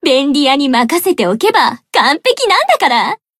BA_V_Aru_Battle_Victory_1.ogg